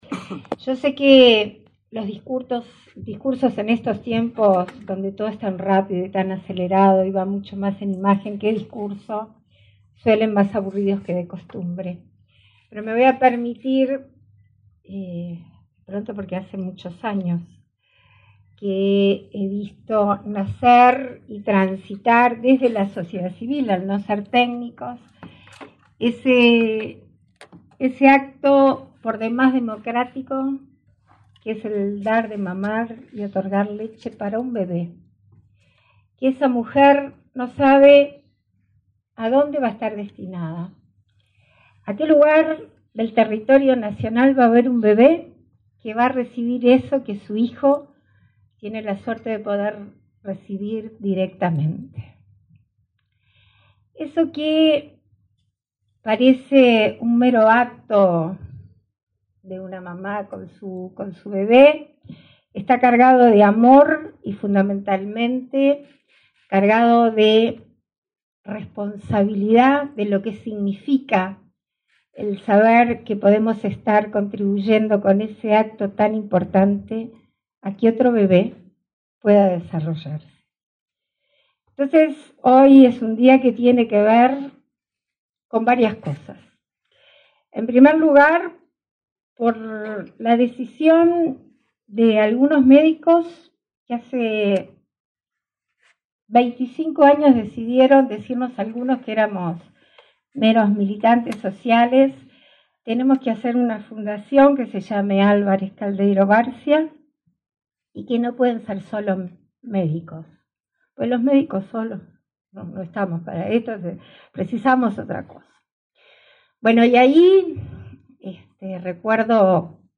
Palabras de la presidenta en ejercicio, Beatriz Argimón
La presidenta de la República en ejercicio, Beatriz Argimón, participó, este lunes 9 en el hospital Pereira Rossell, en el acto de entrega de un sello